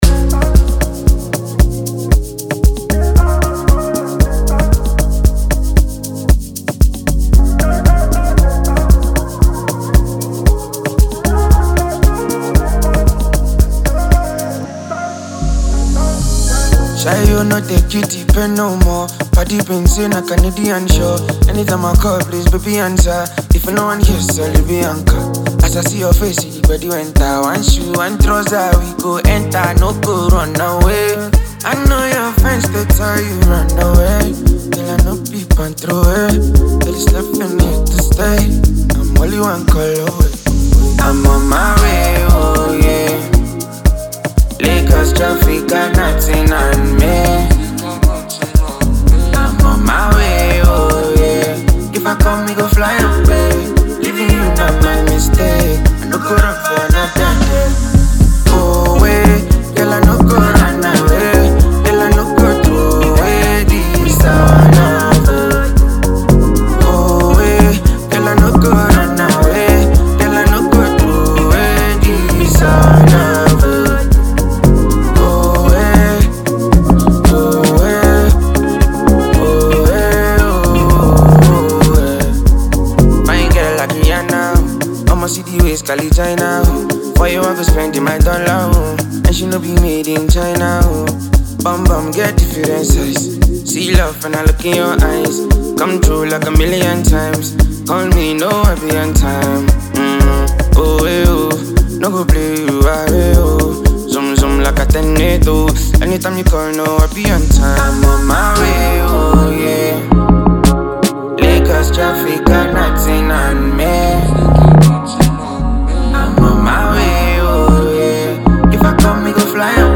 Buzzing Nigerian Afrobeat singer